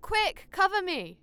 Barklines Combat VA
Added all voice lines in folders into the game folder